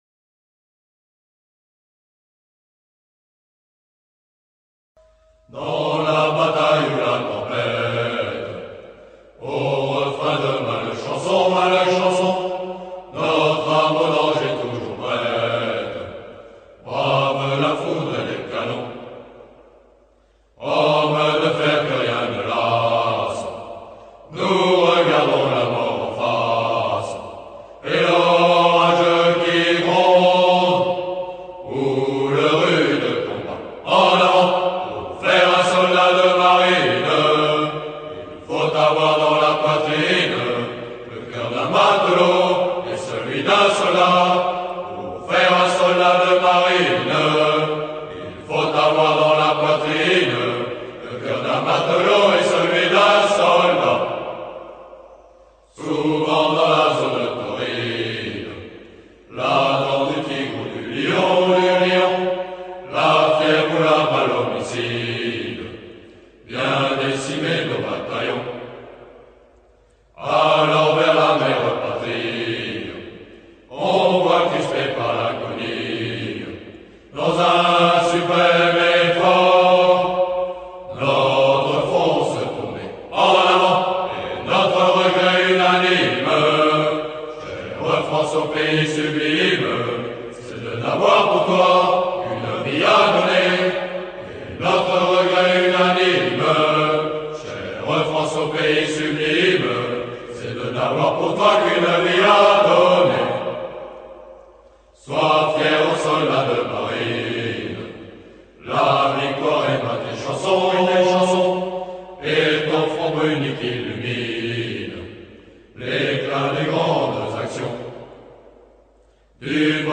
Le chant régimentaire du régiment d'infanterie de marine du Pacifique - Polynésie (le chant des Tamari’i Volontaires) est entonné à l’occasion des couleurs et rassemblements régimentaires et l’Hymne des Troupes de marine durant les visites d’autorités, les repas de corps, les grandes réunions ainsi que la cérémonie de commémoration des combats de Bazeilles, fête des troupes de marine.